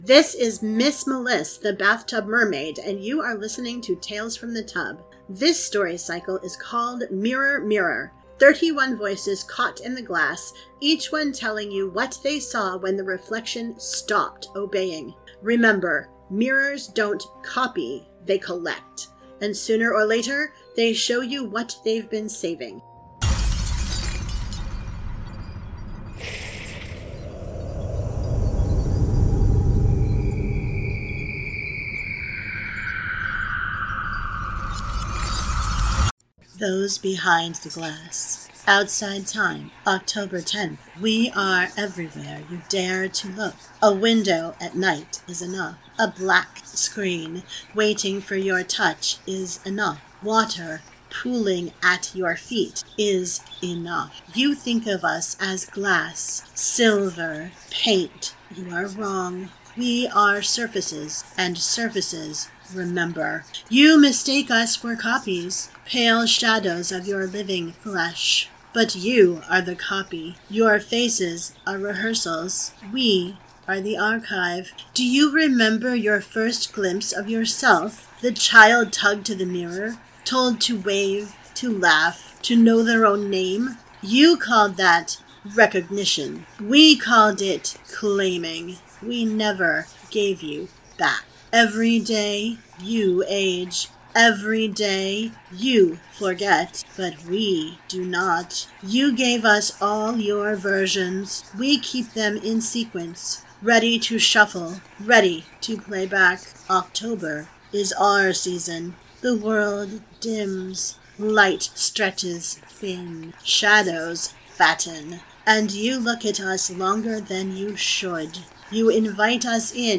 Mirror Mirror is a 31-day audio fiction cycle unfolding through October — one voice, one story, one reflection each day.